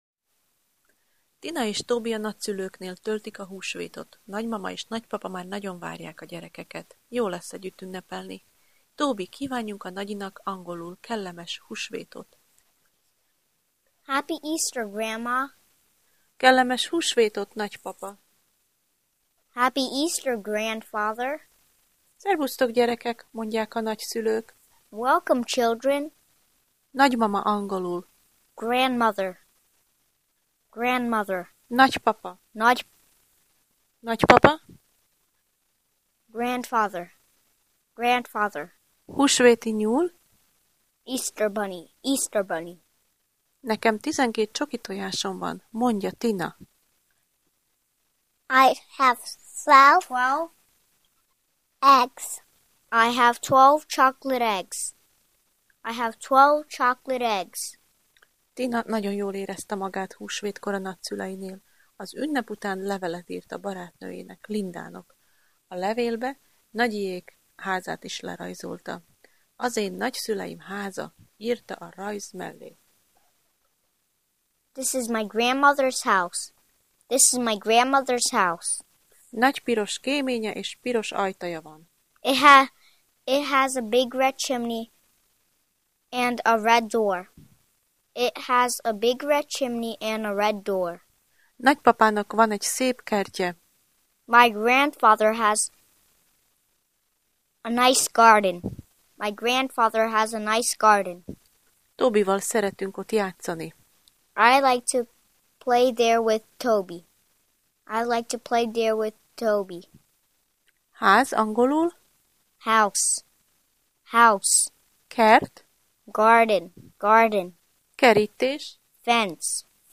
Az ebben a leckében szereplő szavak helyes kiejtését meghallgathatod Tobytól.